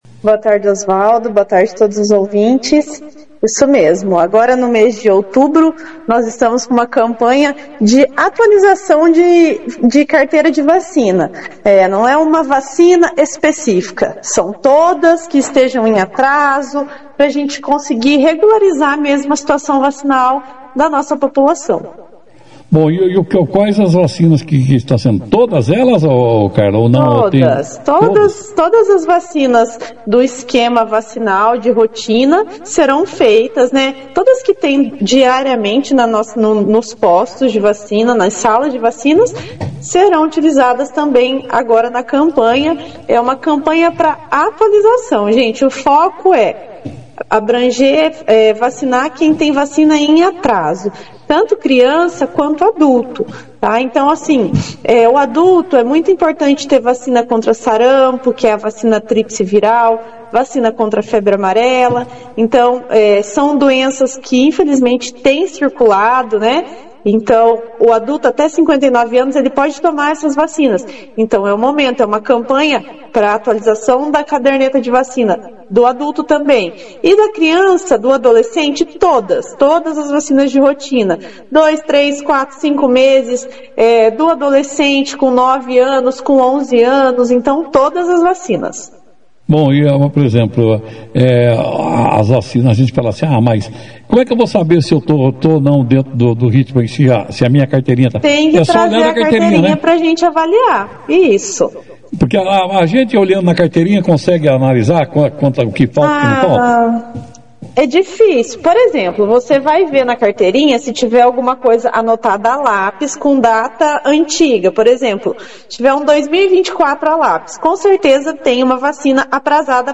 participou da 2ª edição do jornal Operação Cidade nesta sexta-feira (10), apresentando o cronograma completo da Campanha Nacional de Multivacinação para atualização da caderneta de vacinação de crianças e adolescentes.